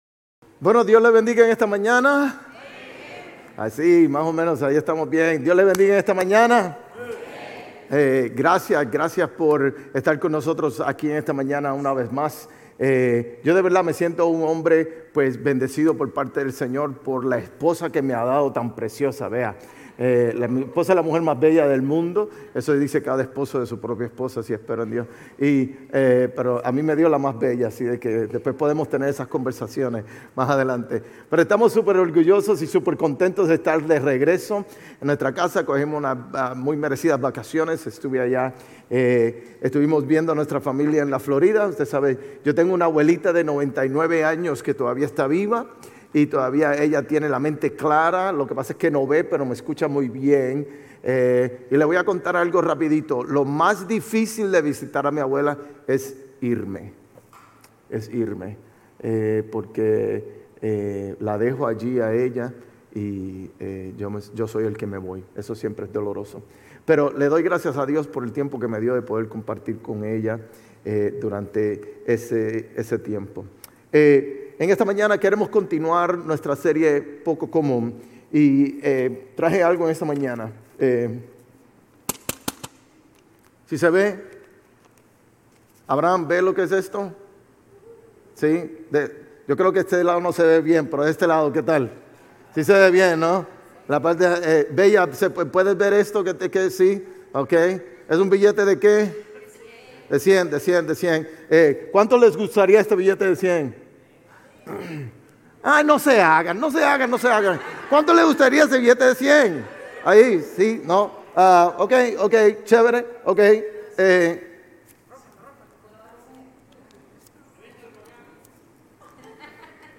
Sermones Grace Español 7_20 Grace Espanol Campus Jul 21 2025 | 00:42:34 1x 00:00 / 00:42:34 Subscribe Share RSS Feed Share Link Embed